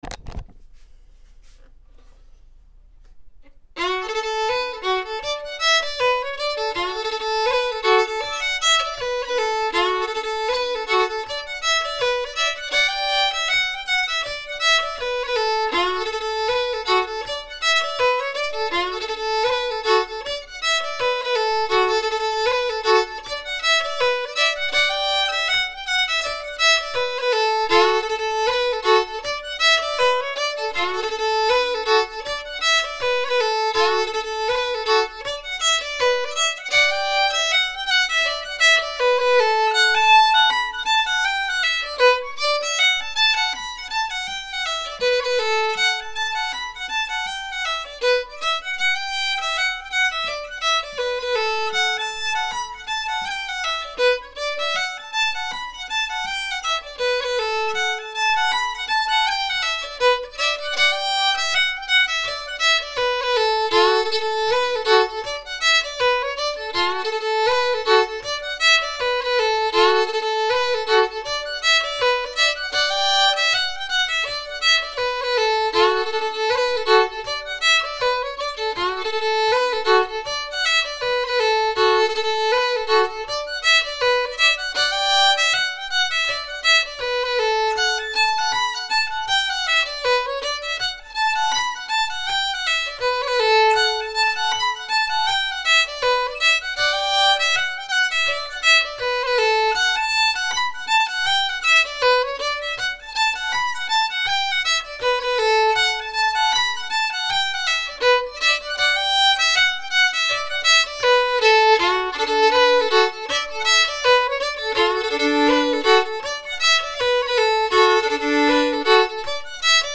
Silver Spear @ 80 bpm – Sean-nós & Set Dance